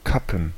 Ääntäminen
Ääntäminen Tuntematon aksentti: IPA: /ˈkapm̩/ IPA: /ˈkapən/ Haettu sana löytyi näillä lähdekielillä: saksa Käännöksiä ei löytynyt valitulle kohdekielelle. Kappen on sanan Kappe monikko.